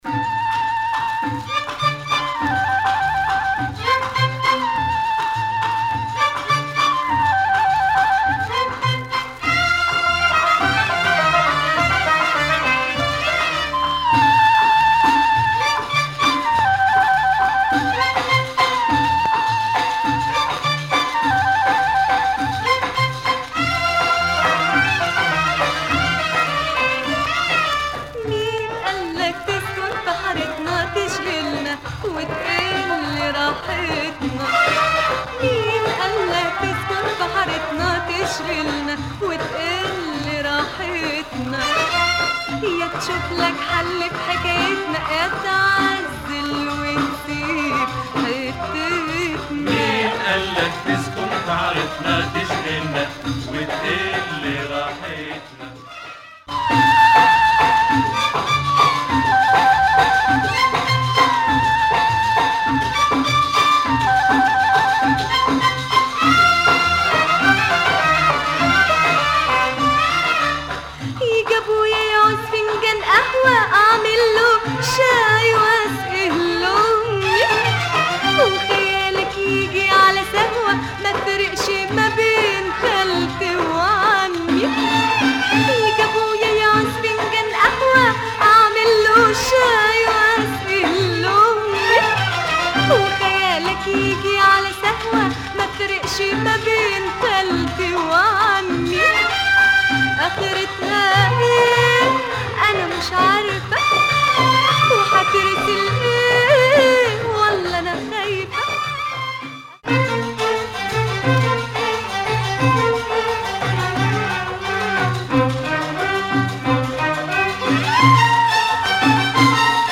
Moroccan female singer
performs the modern Egyptian way
Very Warda soundalike.